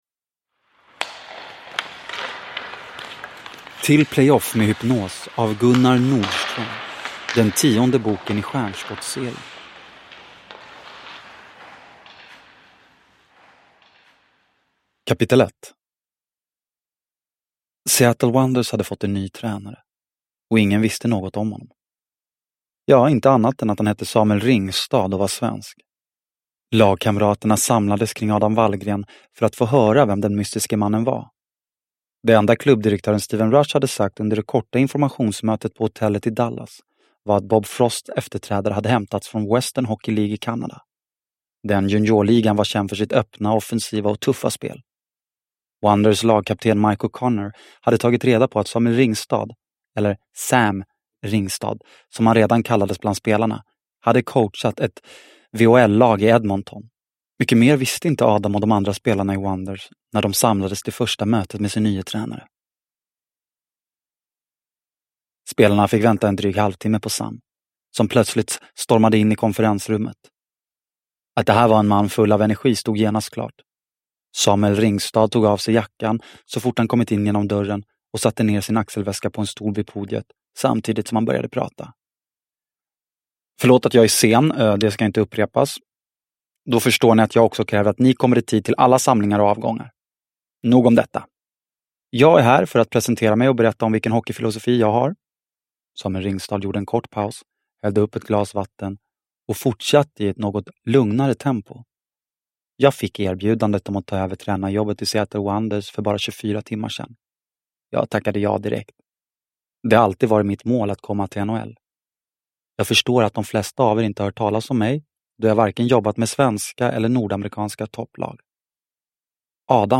Till playoff med hypnos – Ljudbok – Laddas ner